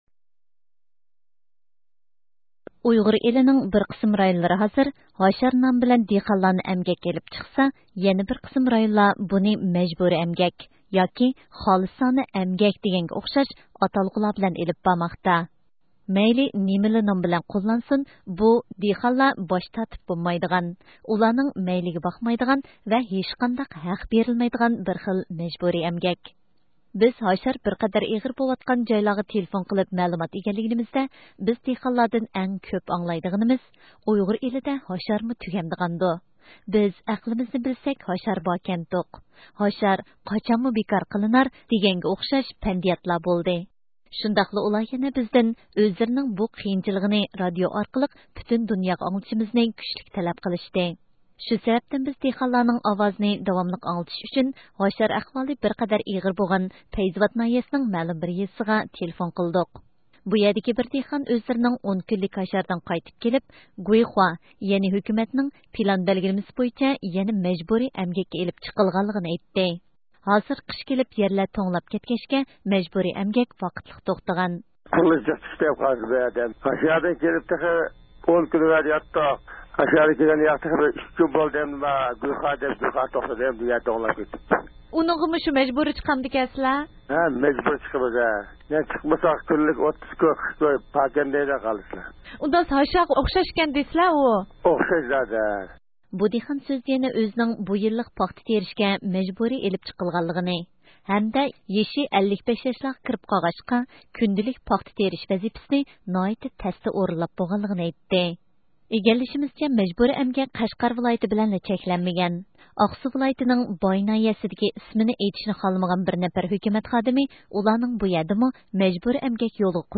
بىز بۇنىڭغا جاۋاب ئىزدەش ئۈچۈن ئۇيغۇر ئاپتونوم رايونلۇق ھۆكۈمەتكە تېلېفون قىلدۇق.
بىز قەشقەر ۋىلايىتىنىڭ يەنە بىر ناھىيىسىگە تېلېفون قىلدۇق.